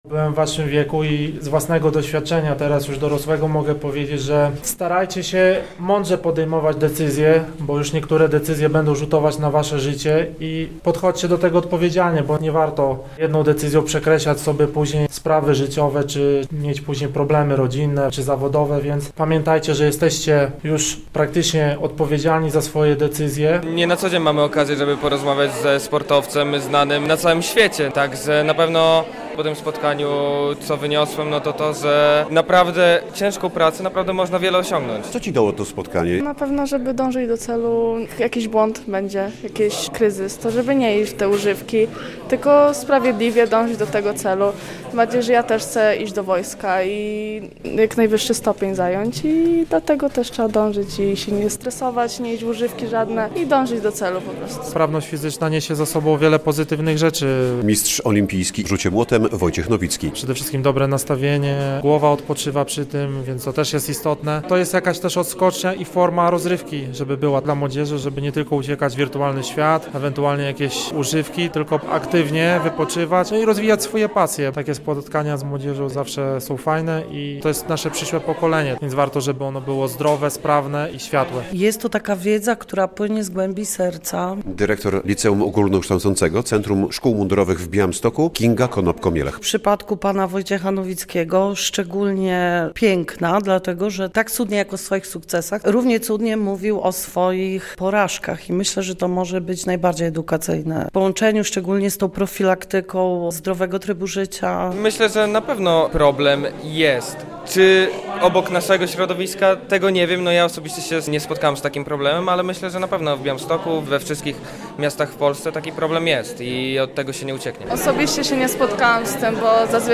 Najlepszą ucieczką od problemów jest sport, a nie dopalacze czy narkotyki - podkreślał na spotkaniu z białostockimi licealistami mistrz olimpijski w rzucie młotem Wojciech Nowicki.
relacja